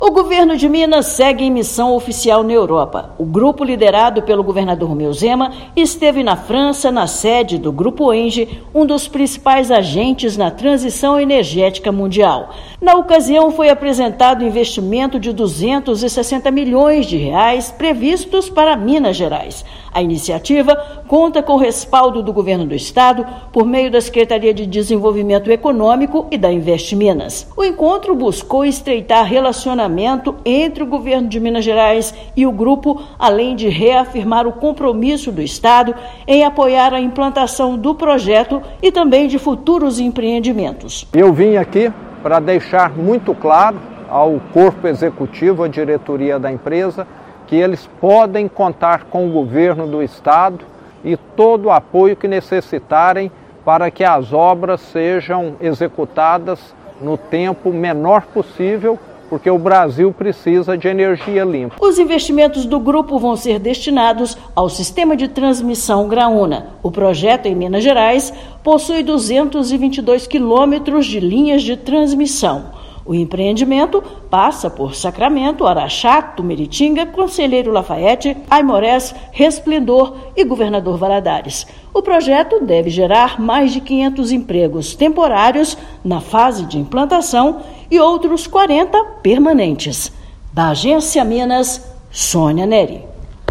Engie apresentou aporte durante missão do Governo de Minas na França. Ouça matéria de rádio.